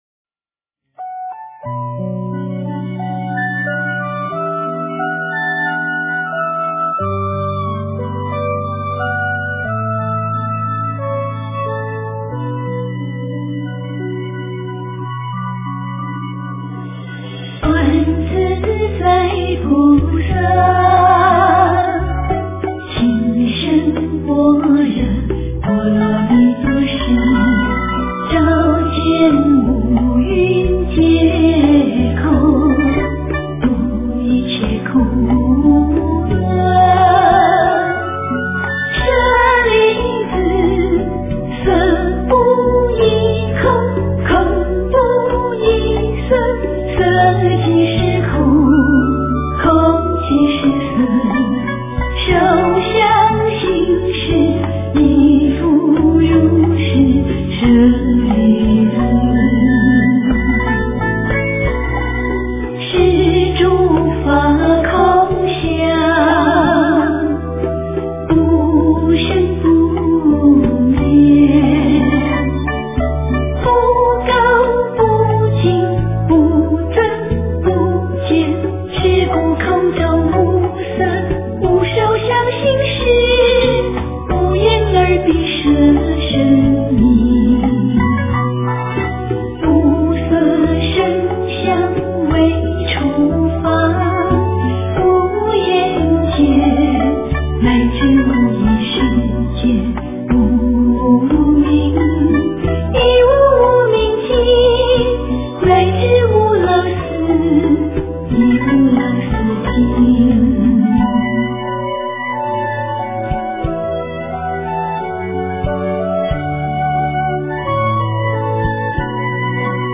诵经
佛音 诵经 佛教音乐 返回列表 上一篇： 大悲咒 下一篇： 大悲咒 相关文章 金刚萨埵--佛陀精神 金刚萨埵--佛陀精神...